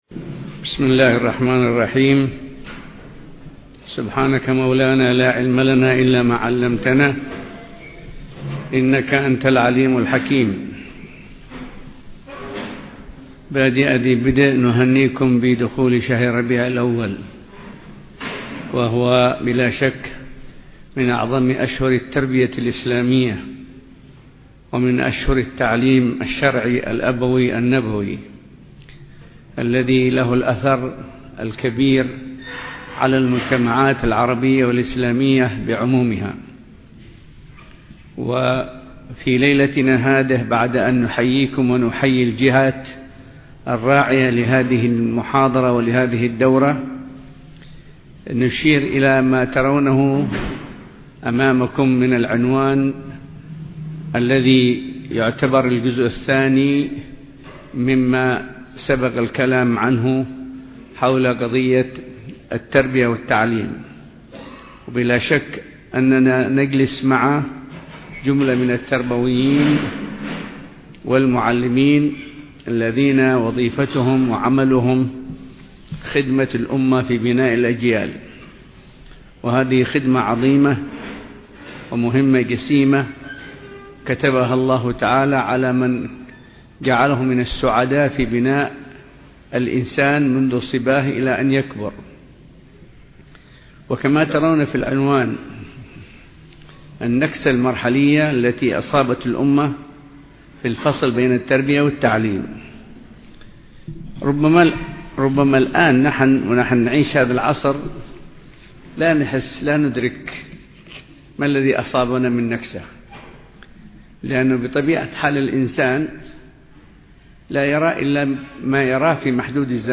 قاعة كلية الشريعة والقانون – تريم
محاضرات فكرية